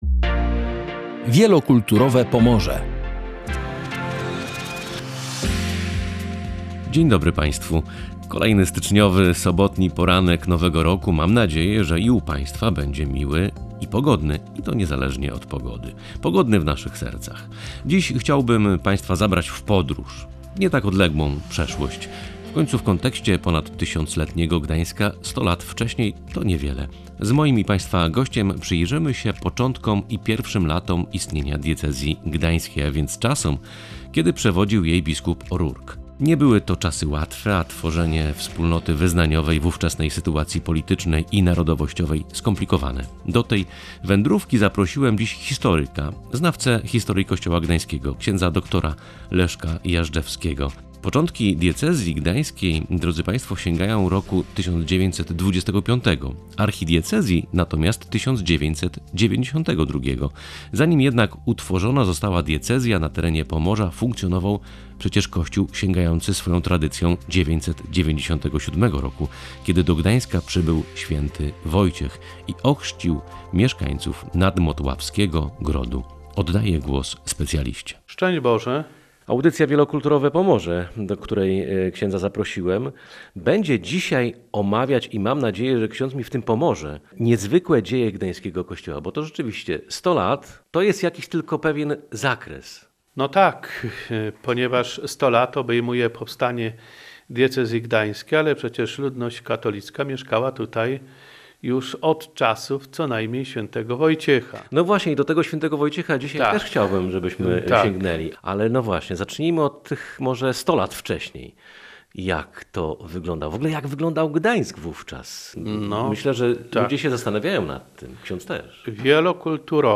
Gościem audycji będzie historyk